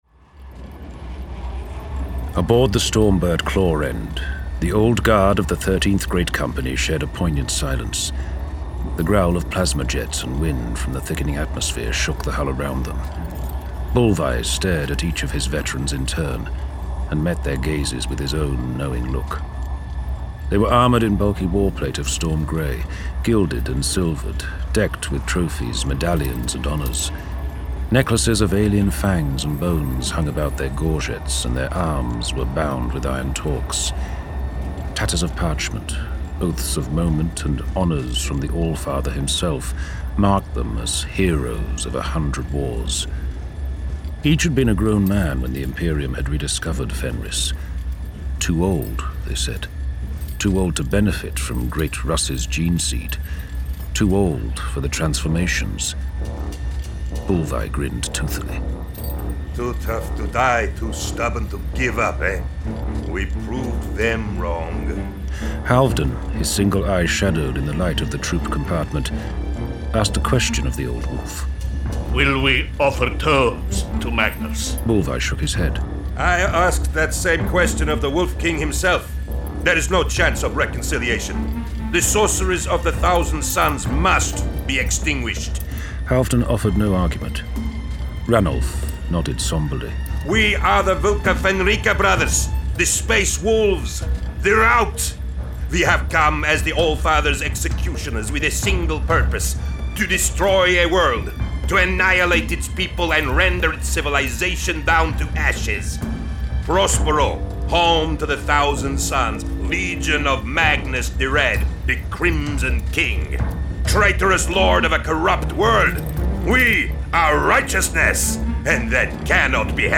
A Horus Heresy audio drama
Кстати на сайт BL есть небольшой, на три с половиной минуты, отрывок из аудиодрамы.